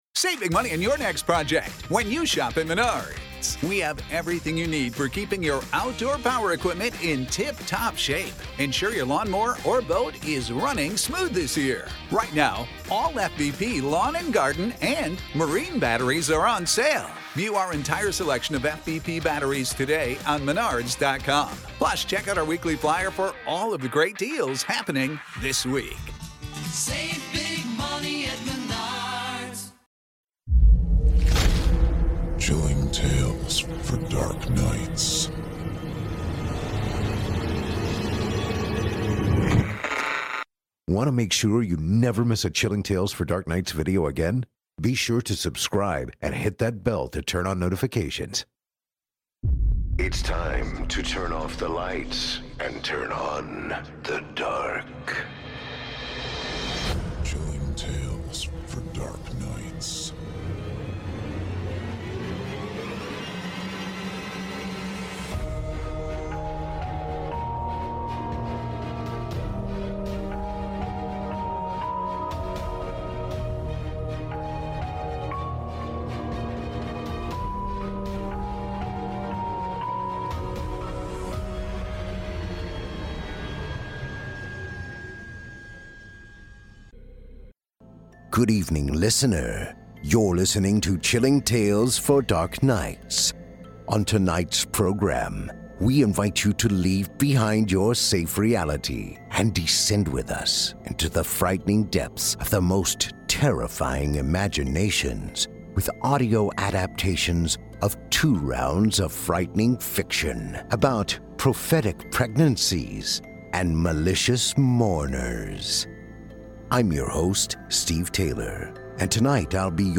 Featuring audio adaptations of two rounds of frightening fiction, about prophetic pregnancies and malicious mourners.